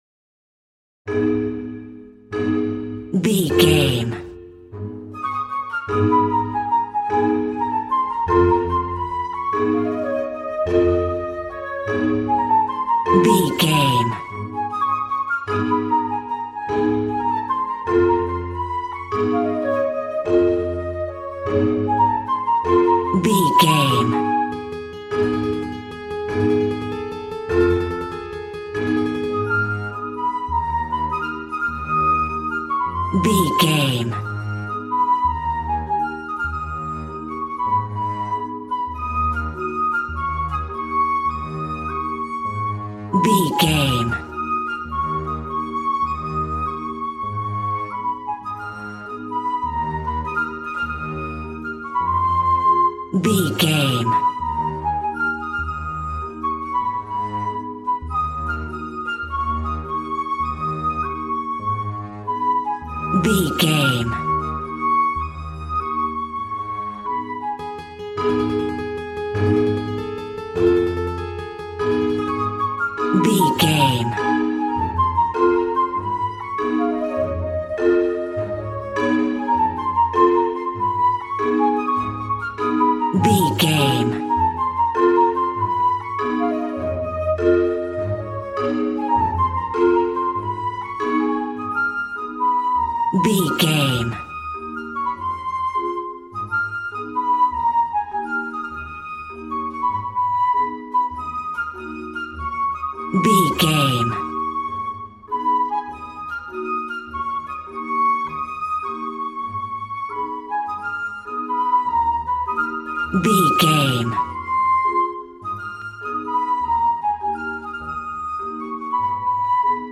Aeolian/Minor
A♭
cheerful/happy
joyful
drums
acoustic guitar